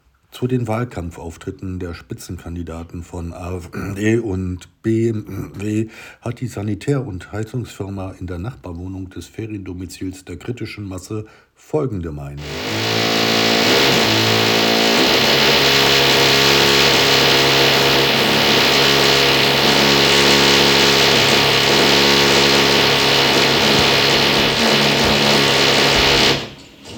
Eine Sanitärfirma in der Nachbarwohnung hasst die Autoritären.